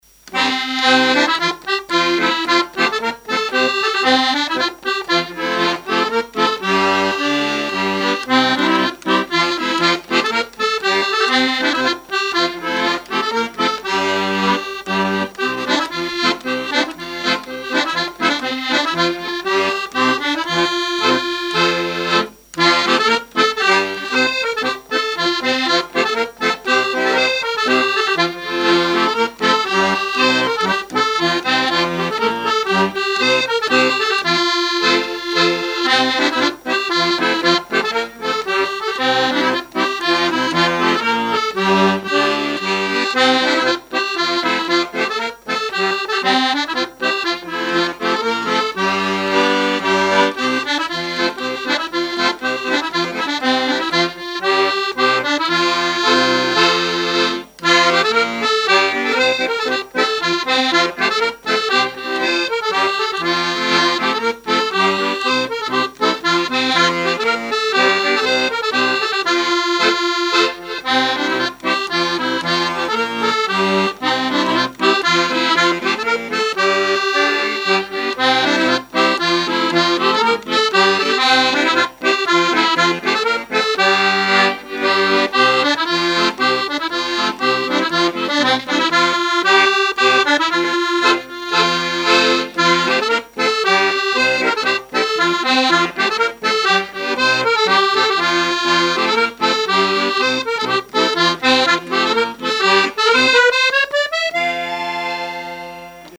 Saint-Christophe-du-Ligneron
danse : ronde : grand'danse ; gestuel : à marcher ;
Genre énumérative
répertoire de chansons, de danses et fables de La Fontaine